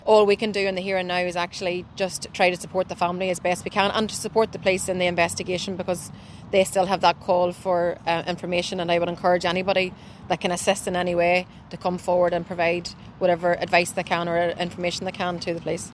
First Minister Michelle O’Neill is urging anyone with information to come forward: